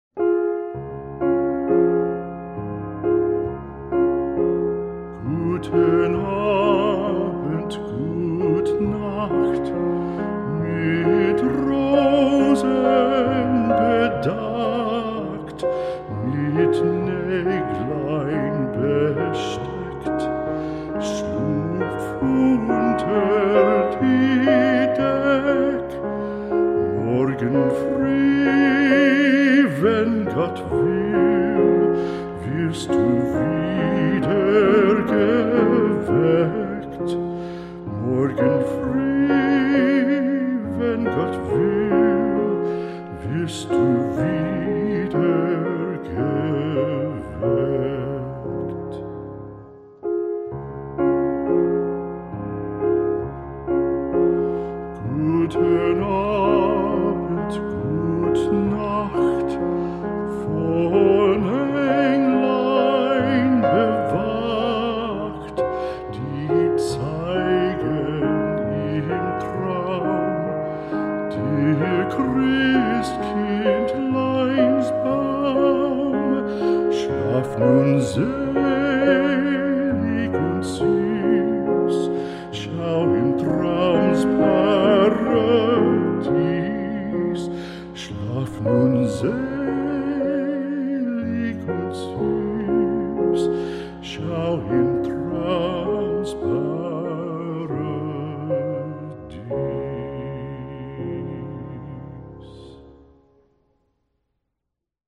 描述：baby toy music box
声道立体声